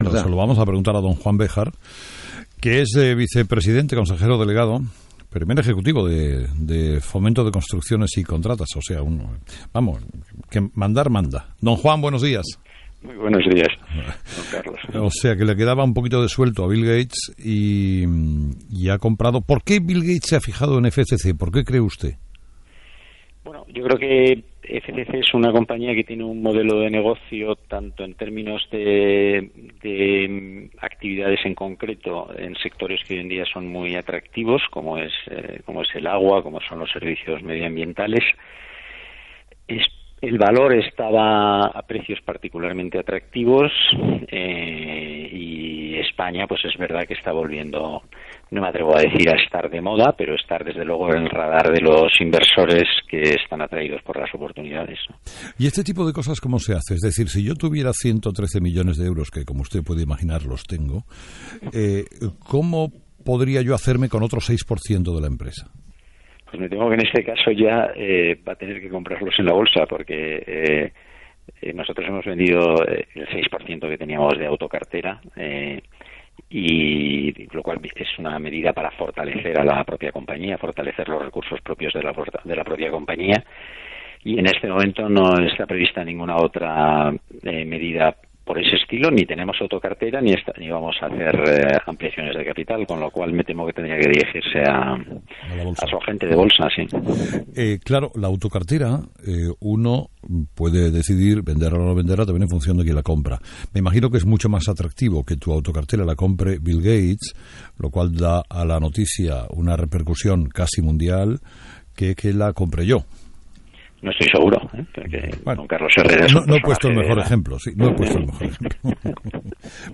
Entrevista